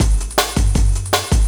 06 LOOP07 -L.wav